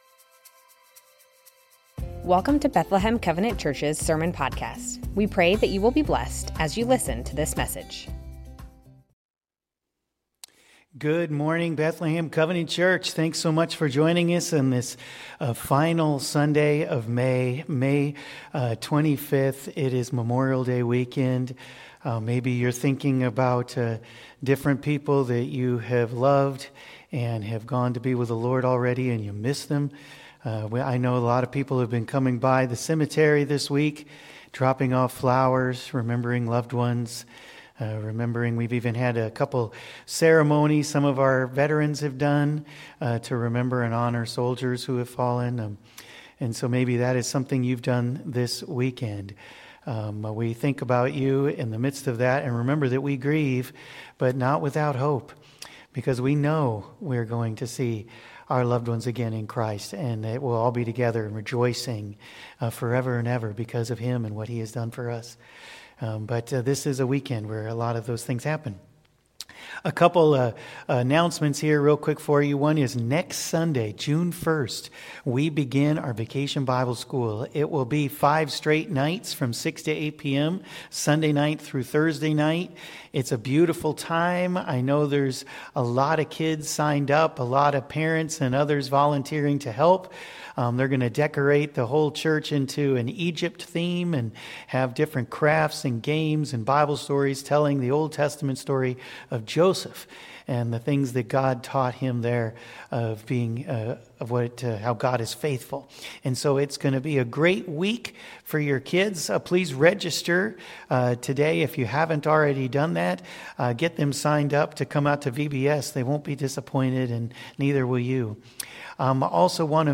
Bethlehem Covenant Church Sermons Matthew 15:1-28 - Clean and Unclean May 25 2025 | 00:32:55 1x 00:00 / 00:32:55 Subscribe Share Spotify RSS Feed Share Link Embed